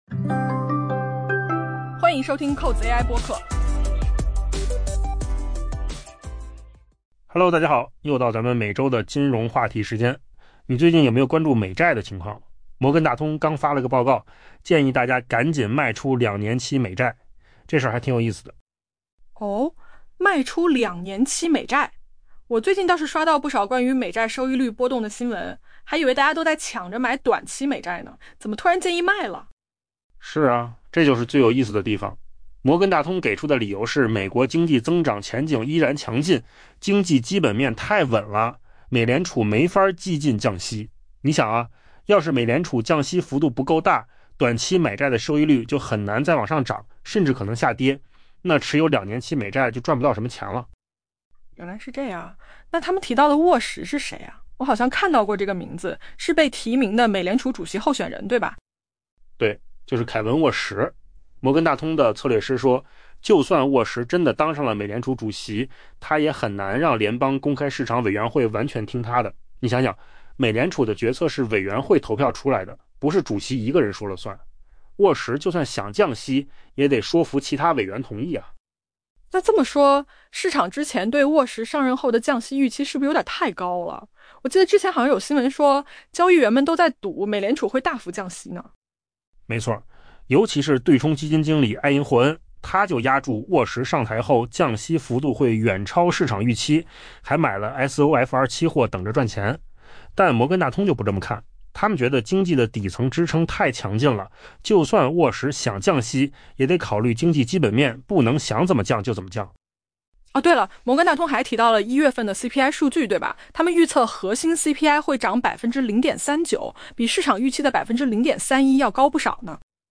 AI播客：换个方式听新闻 下载mp3 音频由扣子空间生成 由于美国经济增长前景依然强劲，摩根大通的策略师建议将卖出2年期美国国债作为一项“战术性”交易。